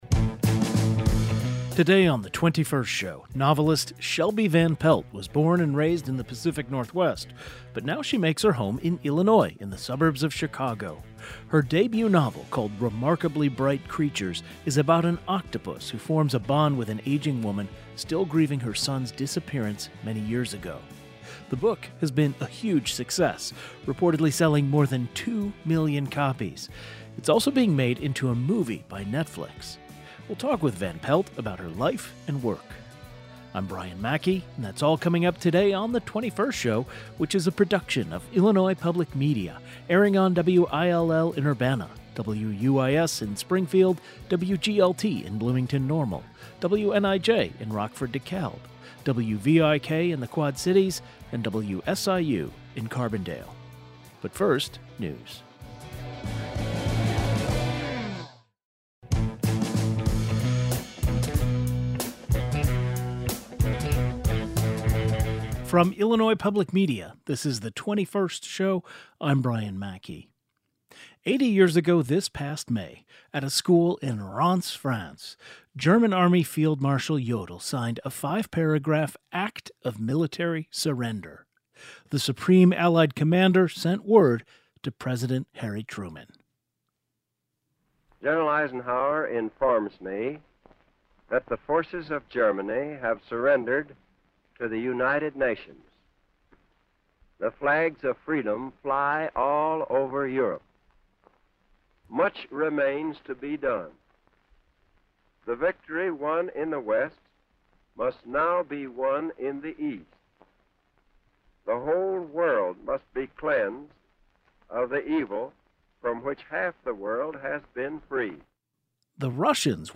It would be known as V-E — Victory in Europe. A historian and author who has written about World War II joins the program. The 21st Show is Illinois' statewide weekday public radio talk show, connecting Illinois and bringing you the news, culture, and stories that matter to the 21st state.
Today's show included a rebroadcast of the following "best of" segment, first aired May 8, 2025: Remembering VE Day 80 years later.